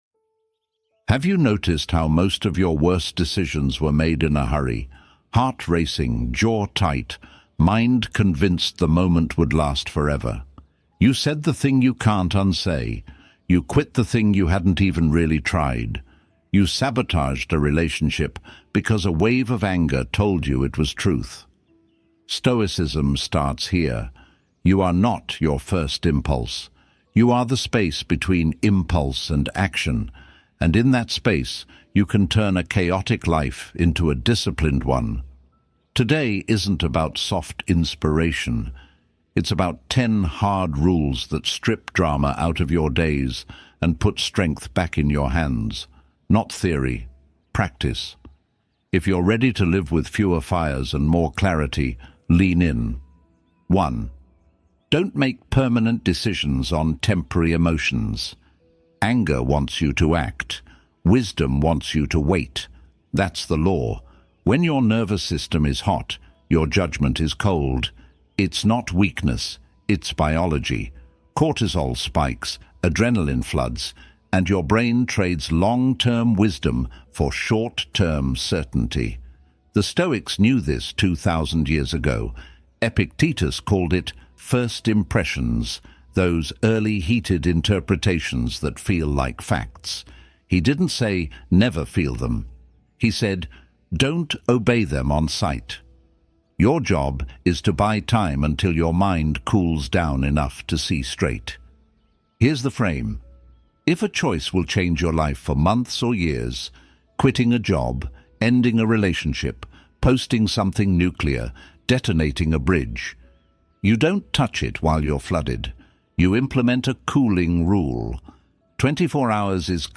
Deep Stoic Sleep Meditations for Total Inner Peace